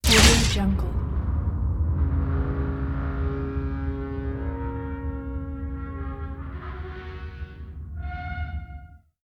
دانلود افکت صدای برخورد لیزر به فلز 1
افکت صدای برخورد لیزر به فلز 1 یک گزینه عالی برای هر پروژه ای است که به صداهای صنعتی و جنبه های دیگر مانند سوزاندن، متالیک و ضربه نیاز دارد.
Sample rate 16-Bit Stereo, 44.1 kHz
Looped No